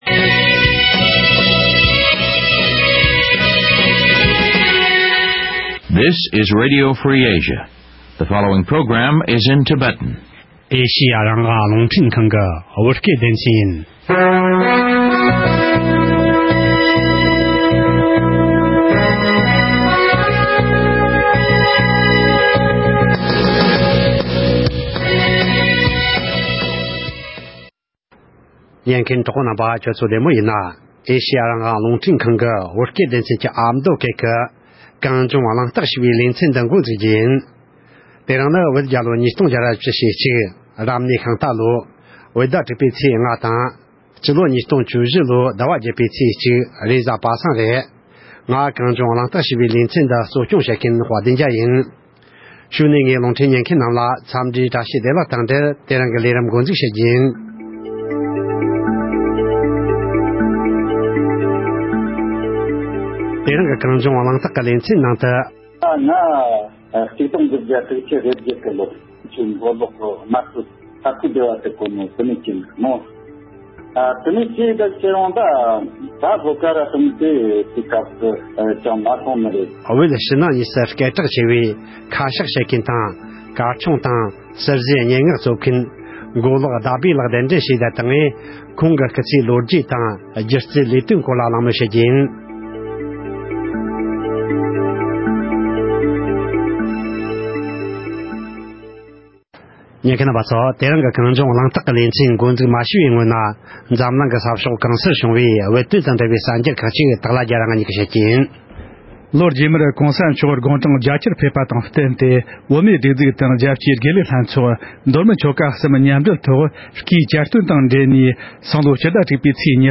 གླེང་བ།